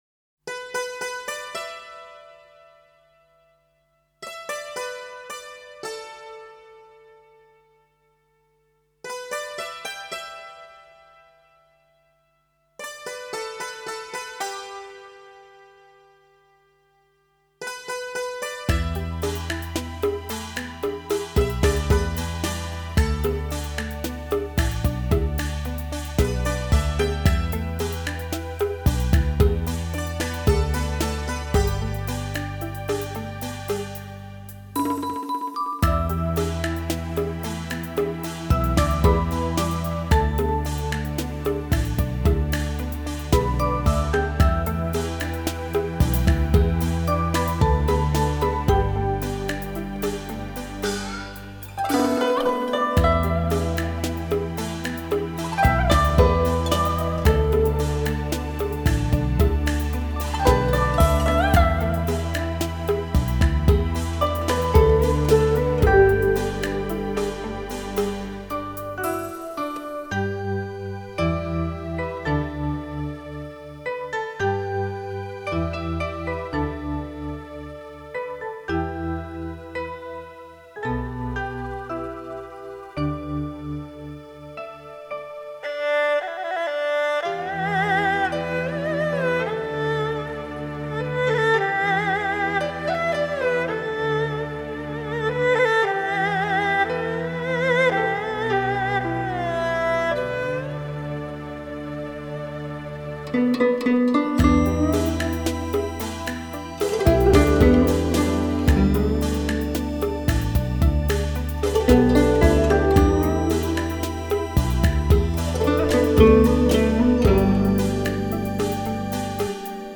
演出：笙、排箫、筝......等